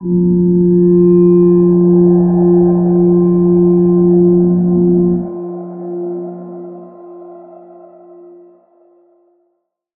G_Crystal-F4-pp.wav